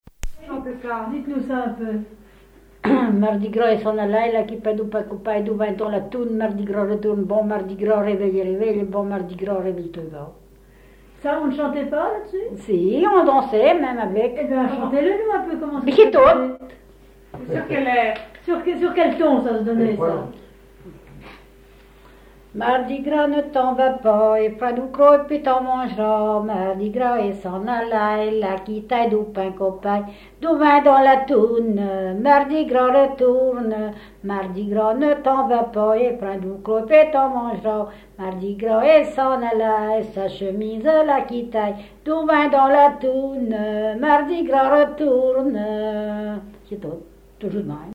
Thème : 0124 - Chants brefs - Mardi-Gras
Fonction d'après l'analyste danse : ronde ;
Usage d'après l'analyste circonstance : carnaval, mardi-gras ; gestuel : danse ;
Genre brève
Catégorie Pièce musicale inédite